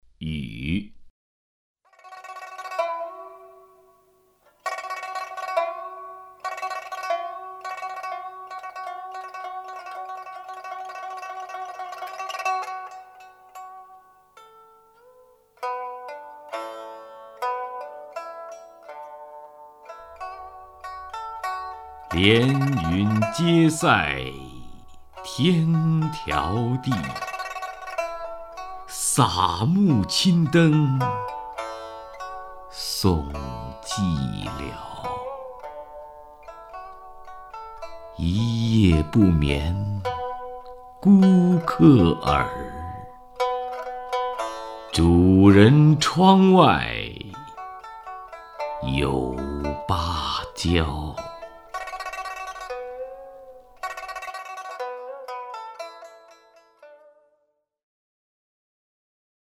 徐涛朗诵：《雨》(（唐）杜牧) (右击另存下载) 连云接塞添迢递， 洒幕侵灯送寂寥。
名家朗诵欣赏